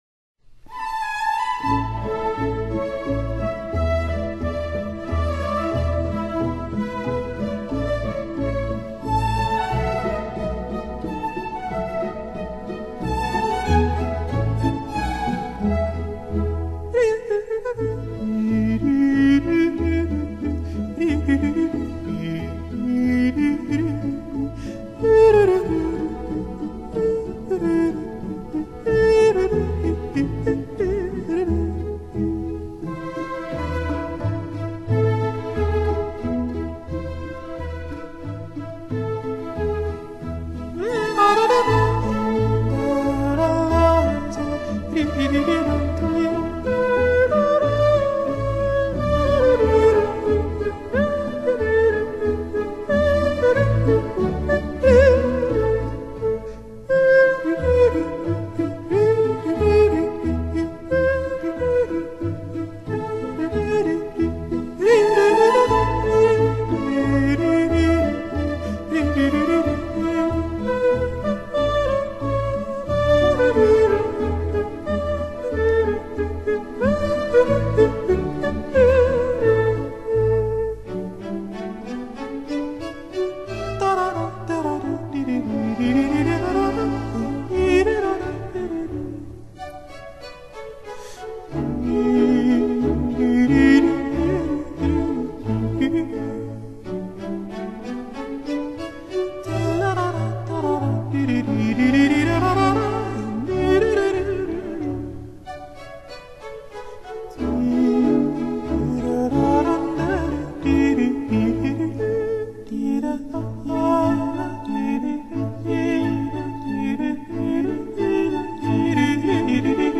擅長以吟唱、歌聲、拍掌、頓足、搥胸，種種技法製造共鳴